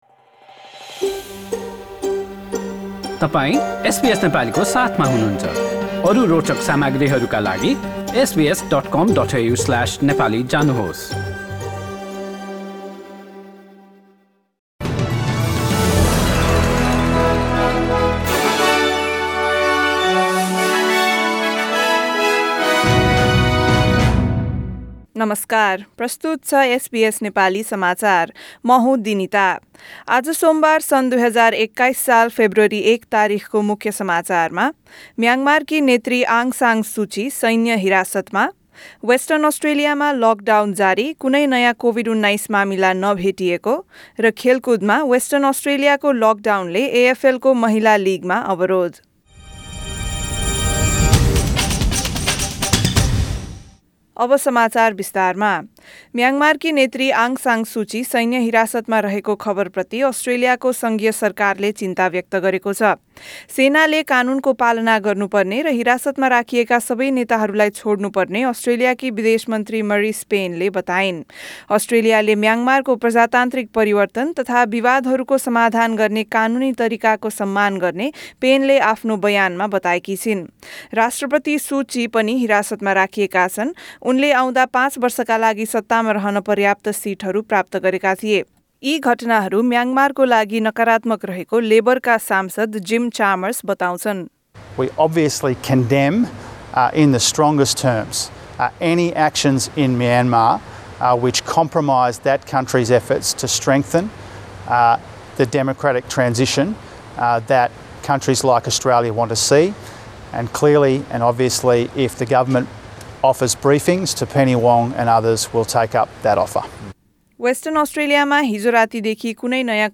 एसबीएस नेपाली अस्ट्रेलिया समाचार: सोमबार १ फेब्रुअरी २०२१